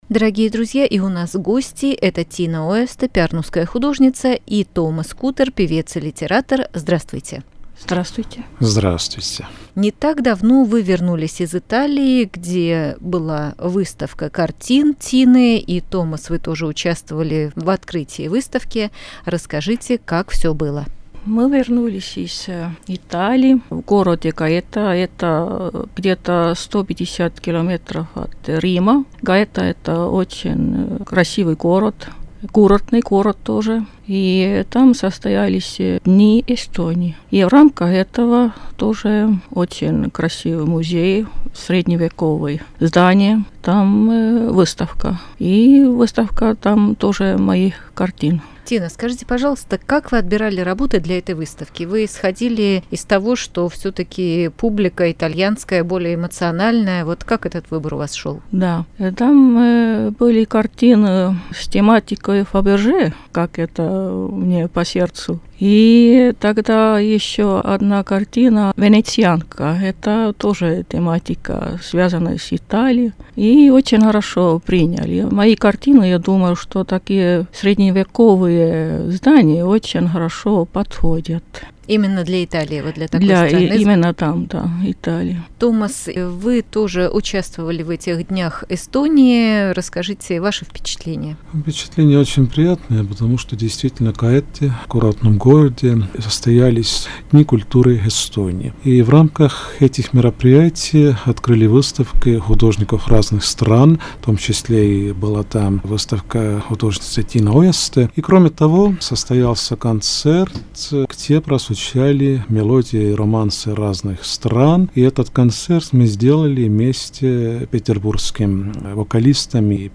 Радио 4, интервью